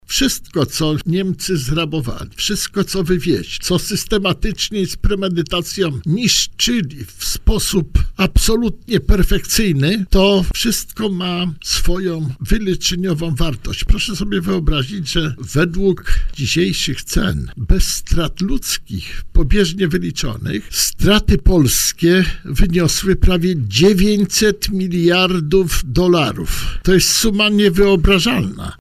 Reparacje za szkody poniesione podczas drugiej wojny światowej były tematem rozmowy w poranku „Siódma9” na antenie Radia Warszawa.